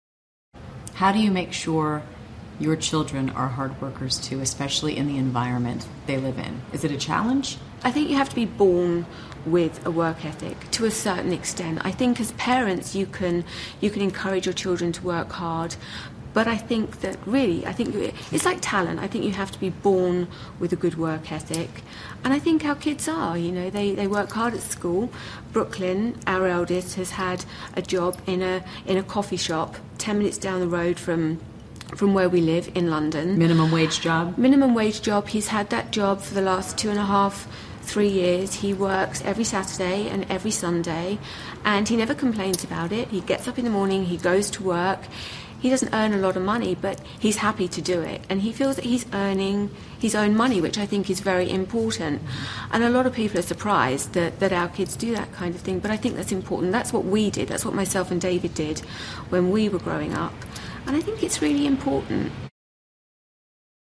访谈录 维多利亚·贝克汉姆育儿经 听力文件下载—在线英语听力室